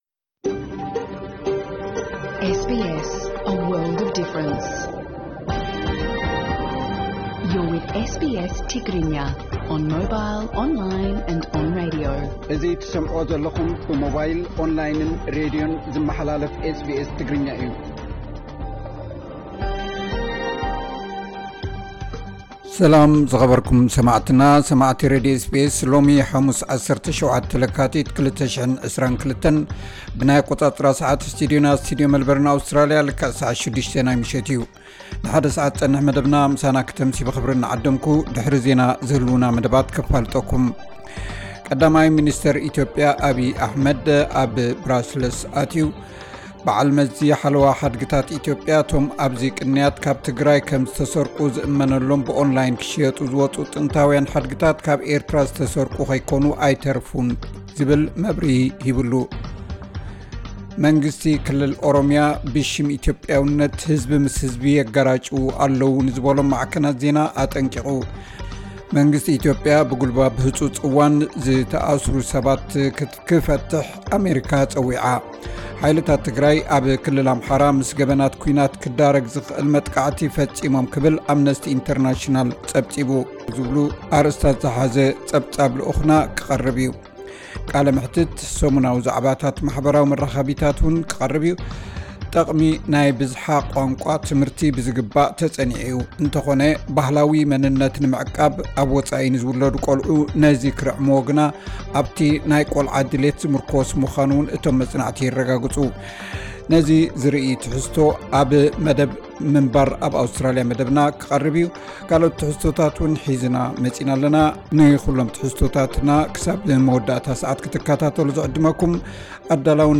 ዕለታዊ ዜና SBS ትግርኛ (17 ለካቲት 2022)